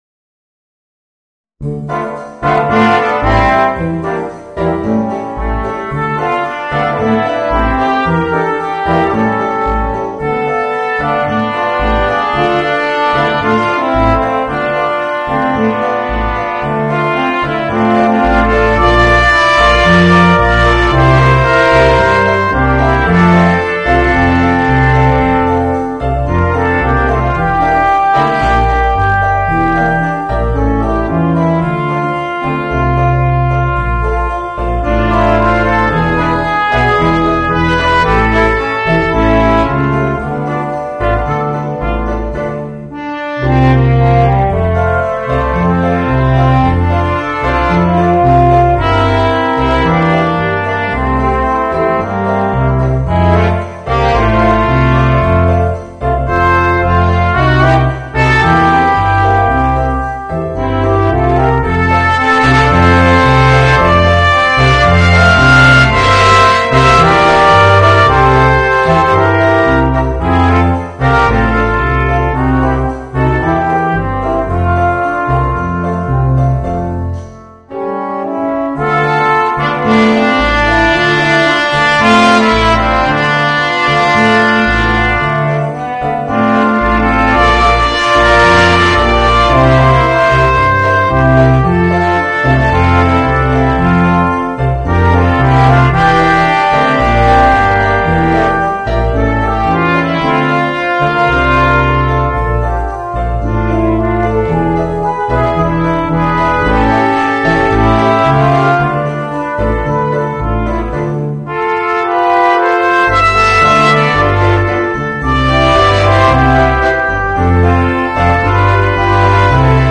Voicing: 2 Trumpets, Trombone and Tuba